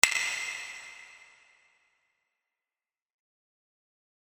カッー2 かんなの背を叩く (+金属)リバーブ
C414